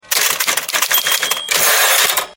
Звук печатной машинки - Typewriter
741_typewriter.mp3